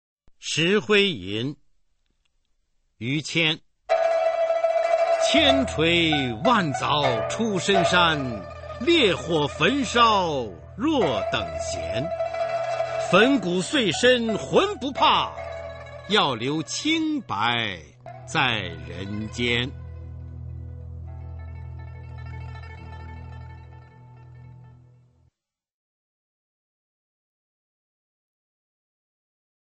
[明代诗词诵读]于谦-石灰吟(男) 朗诵